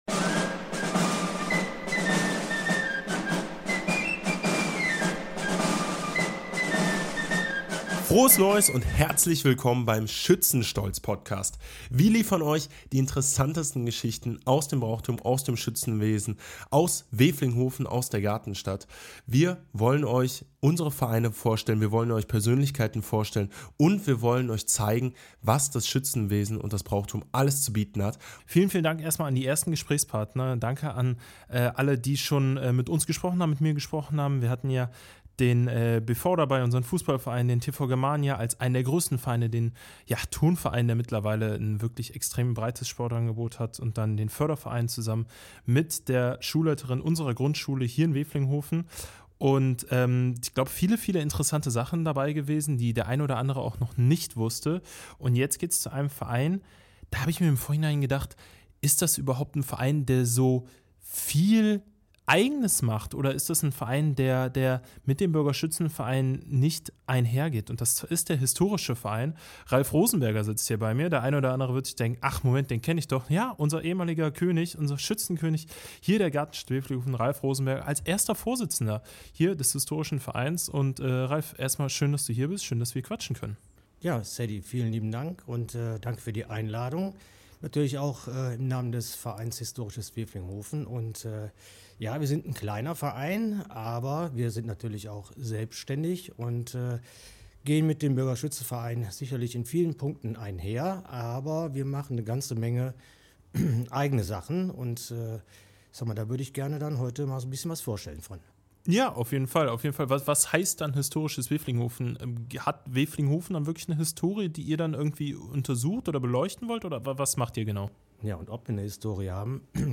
Geschichte sichtbar machen – Der Historische Verein Wevelinghoven im Gespräch (#46) ~ Schützenstolz Podcast